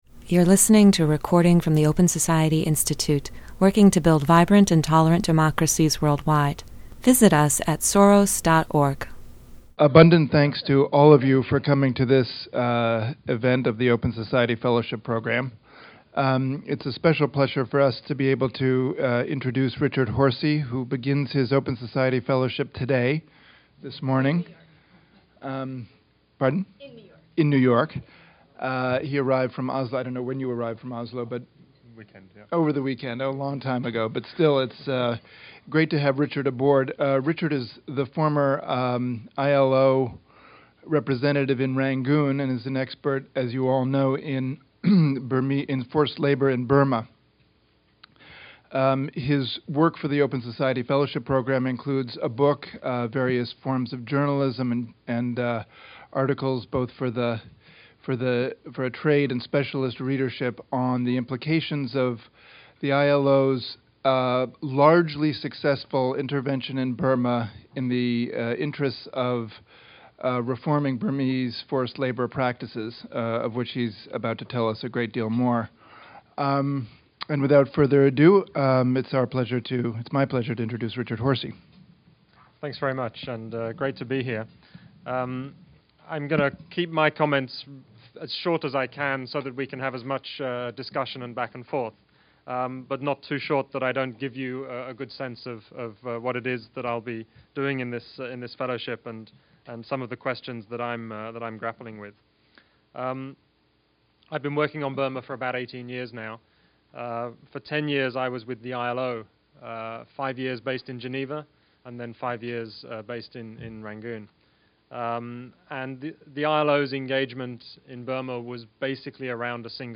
Note: The audio for this event has been edited.